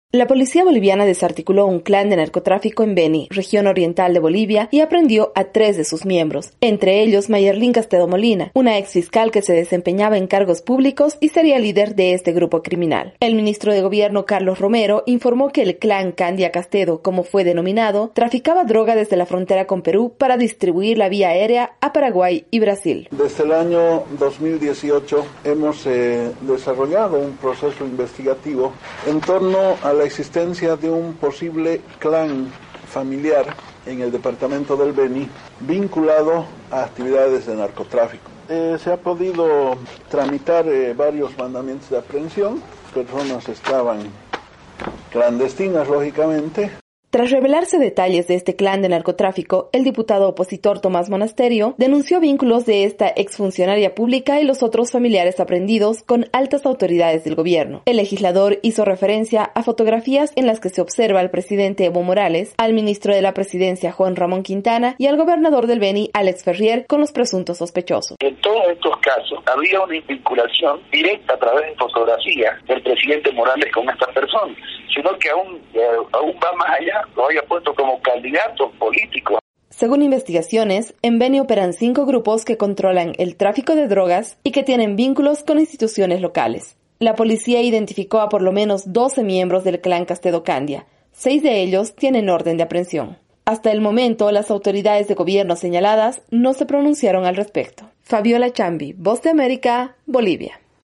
VOA: Informe desde Bolivia